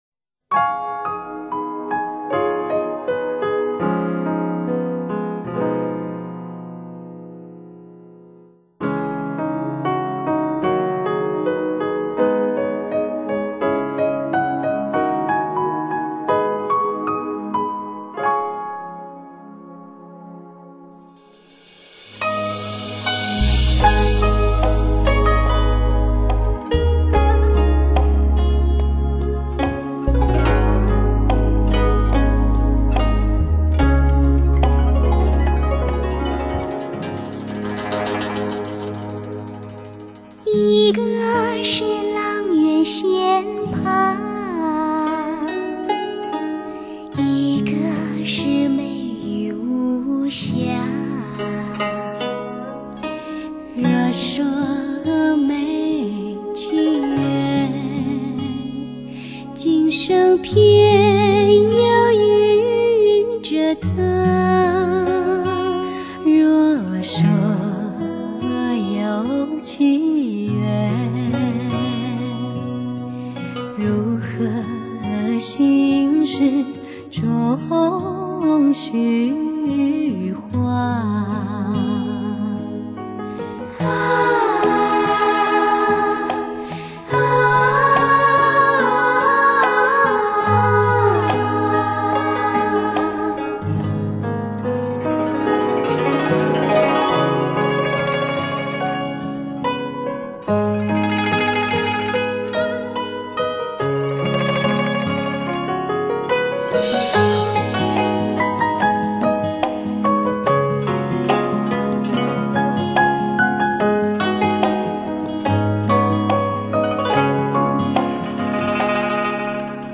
甜美悦耳的少女之声，在器乐的质感拥抱中，
漫过纯真往事，歌声越发圆润和甜美……